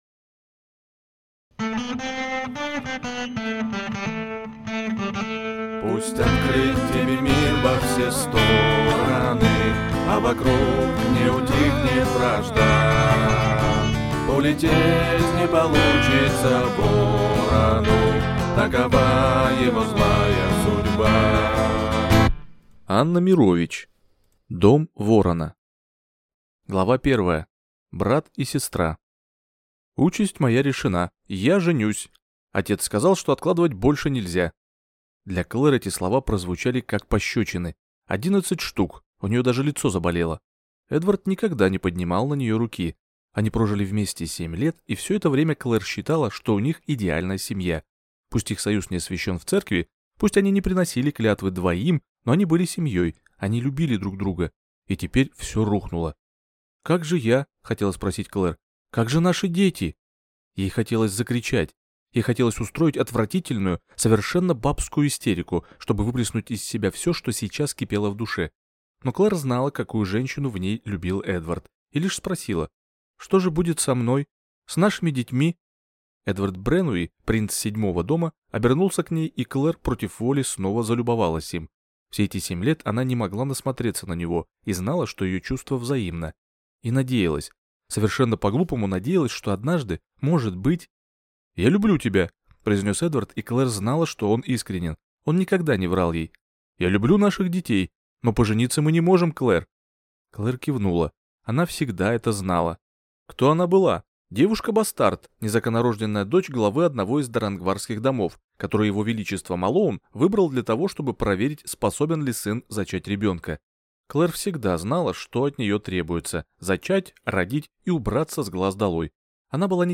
Аудиокнига Дом Ворона | Библиотека аудиокниг